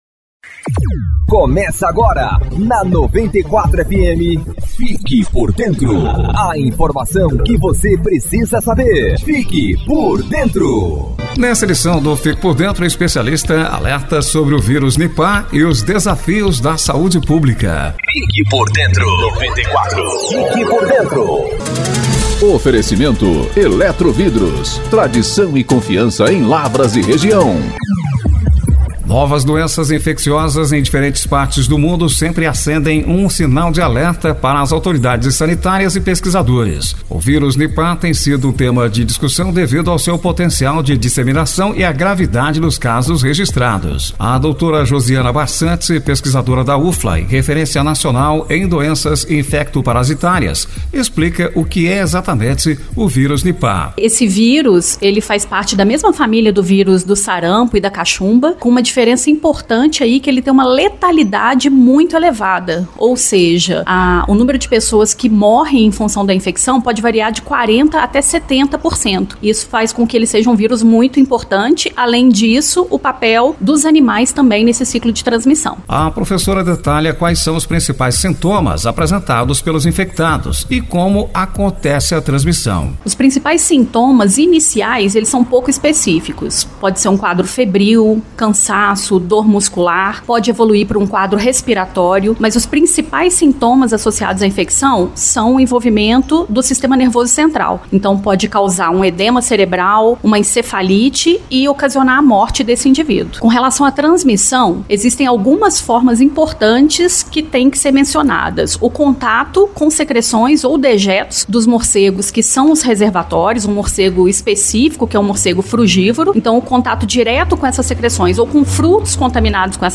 Novas doenças infecciosas ao redor do mundo têm colocado autoridades de saúde em alerta, e o Vírus Nipah (NiV) é o mais recente tema de preocupação internacional devido à sua gravidade. Em entrevista ao programa “Fique Por Dentro”